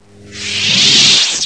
Soundscape: Paranal software sounds
There are three distinctive sounds at the Paranal Control Room during a night of observations: conversations between astronomers and telescope operators, chairs rolling from one desk to another to check different stations, and the sound of the instruments’ software—something very distinctive at Paranal and not very common on other observatories.
The software of each instrument on Paranal plays certain sounds when there is some action required by the operators, or something goes wrong, or something was successful.
Soundscape Mono (wav)
ss-paranal-software-propagate-stop_mono.mp3